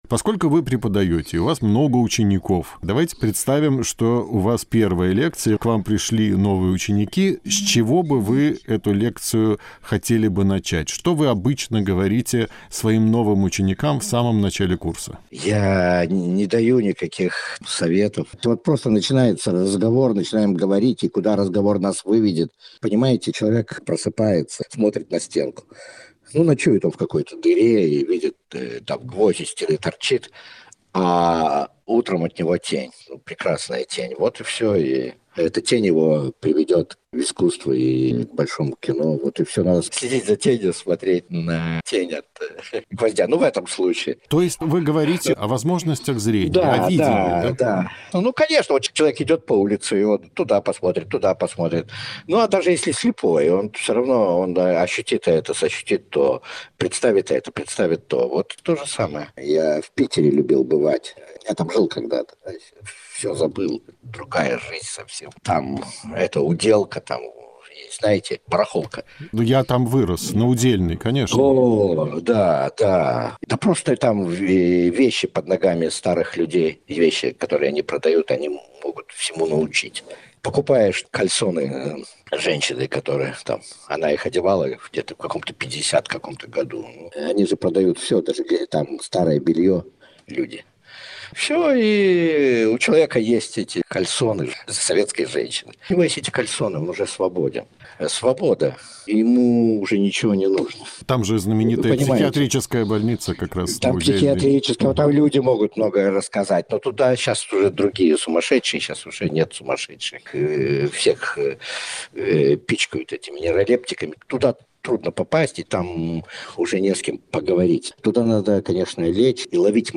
Кинорежиссера Артура Аристакисяна мой звонок застал в Париже, где он с недавних пор живет в спартанских условиях. Но жалоб я не услышал, потому что бедность, в том числе и крайние ее формы, – одна из главных тем кинематографа Аристакисяна.